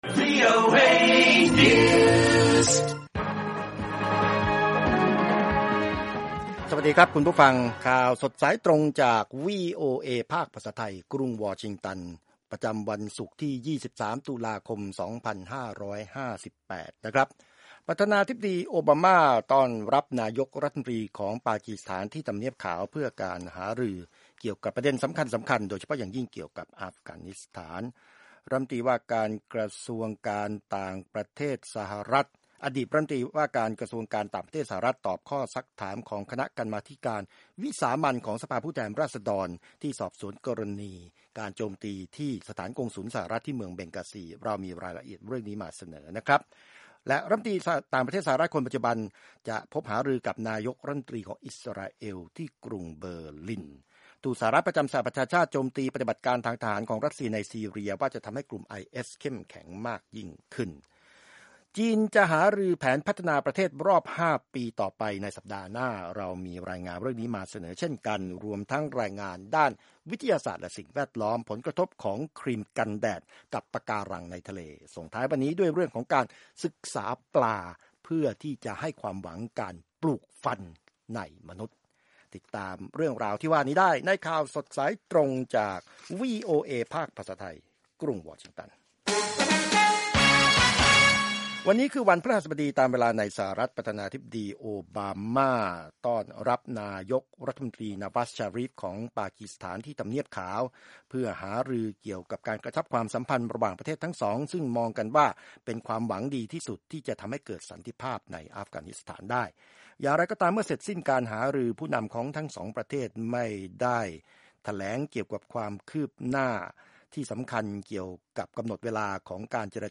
ข่าวสดสายตรงจากวีโอเอ ภาคภาษาไทย 6:30 – 7:00 น. สำหรับวันศุกร์ที่ 3 ตุลาคม 2558